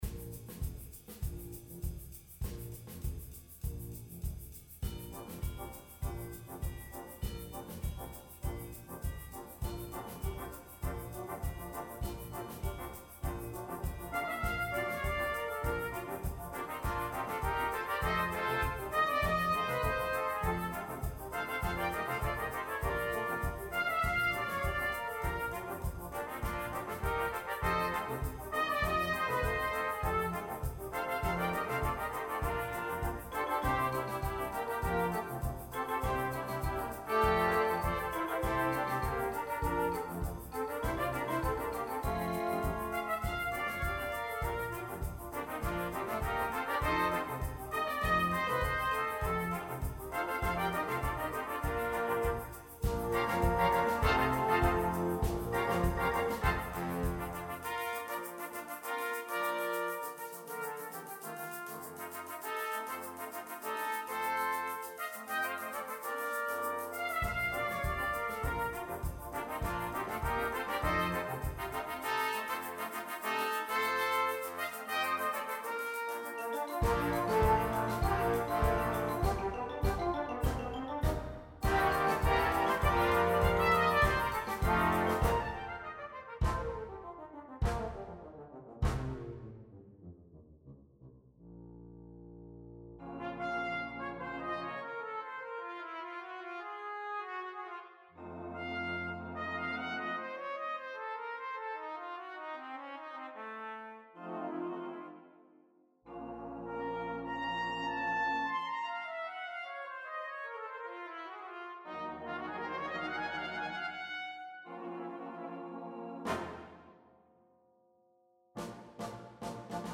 • 2 gedämpfte Kornette (spielen auswendig)
Besetzung: Cornet Trio & Brass Band